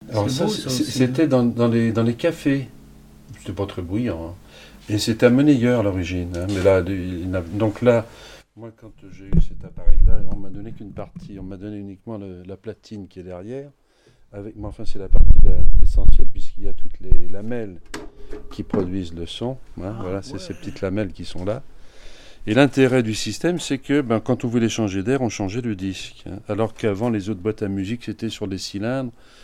Présentation d'une boîte à musique dite piano fantome
Catégorie Témoignage